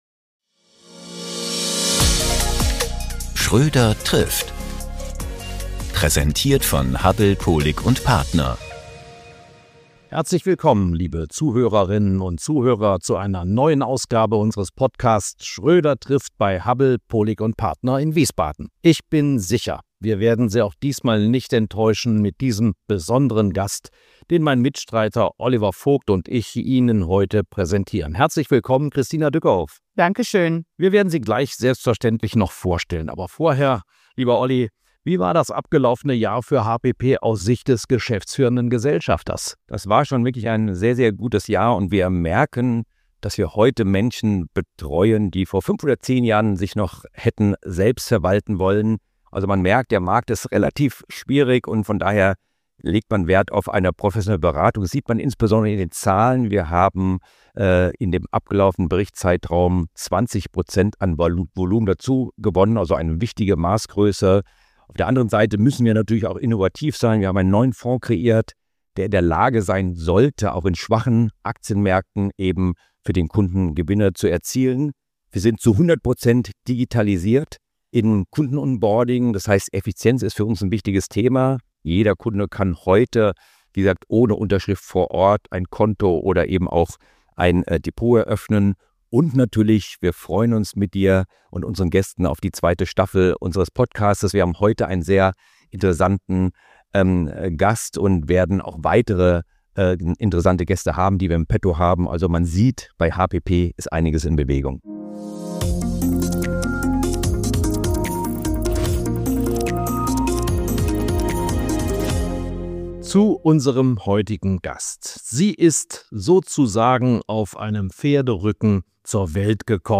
inspirierenden Gespräch